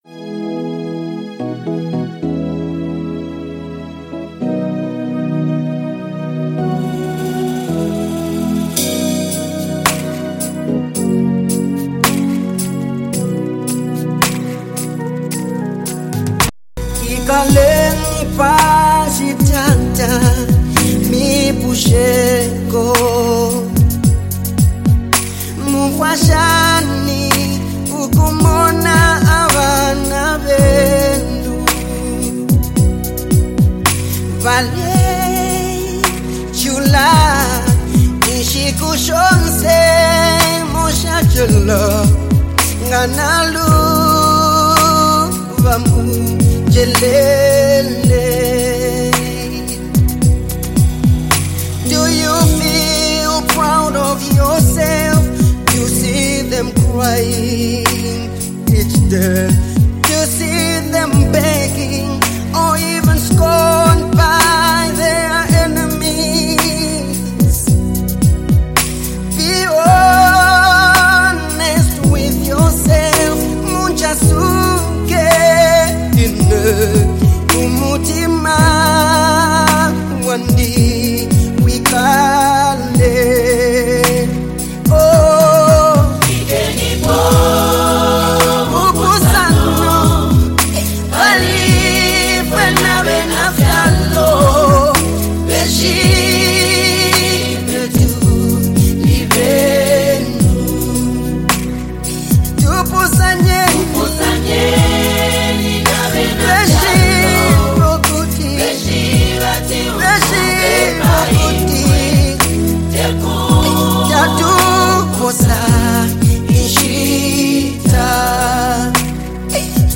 Zambian gospel
spirit-filled song
Through powerful lyrics and a soothing melody